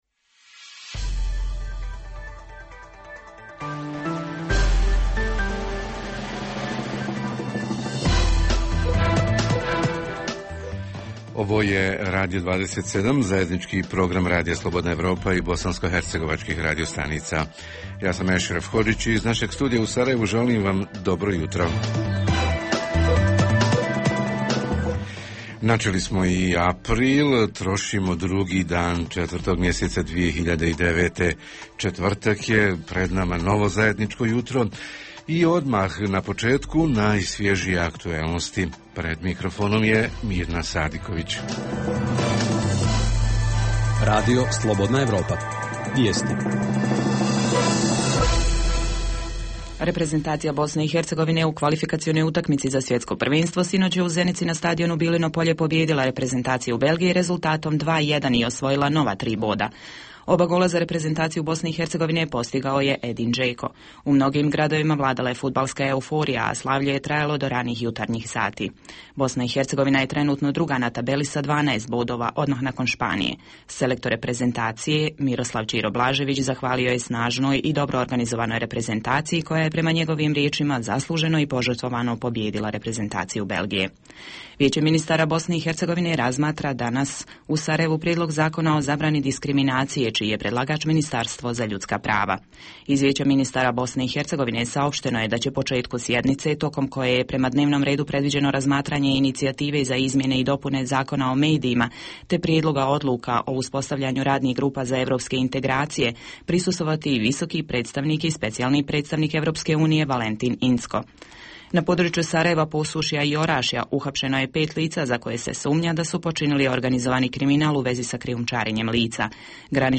Jutarnji program za BiH koji se emituje uživo. Proteste najavljuju metalci, štrajkovi upozorenja nastavnika i ljekara, protestirali su invalidi i sve je više firmi u kojima radnici obustavom rada iznuđavaju isplatu zaostalih plaća i uplatu doprinosa – da li je na pomolu socijalni bunt širih razmjera?
Redovni sadržaji jutarnjeg programa za BiH su i vijesti i muzika.